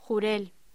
Locución: Jurel
voz